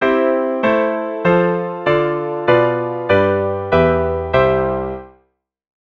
The basic pattern of a descending 5-6 sequence (with intervening chords removed) in C major. The pattern in the lower staff descends by a third each time in this sequence.
Descending_thirds.wav.mp3